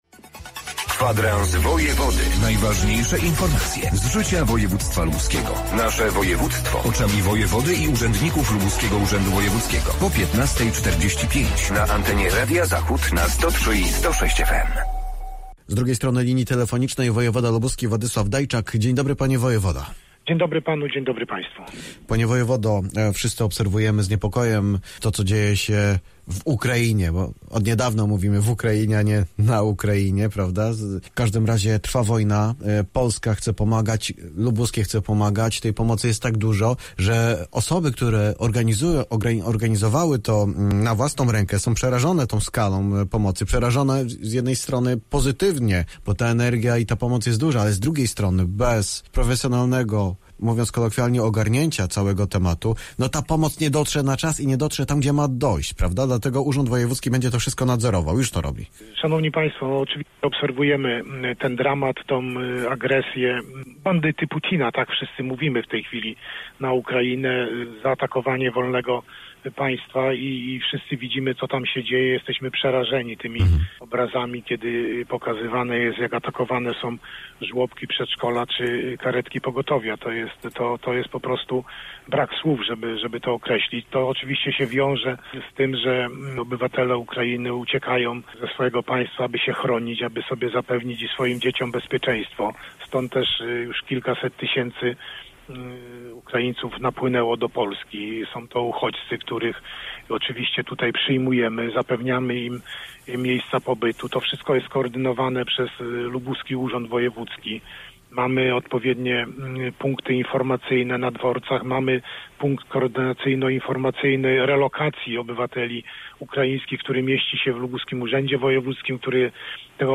Władysław Dajczak, wojewoda lubuski